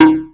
Hit7.wav